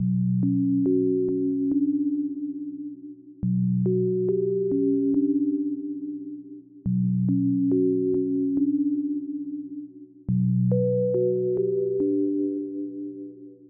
2nd hand 140bmin.wav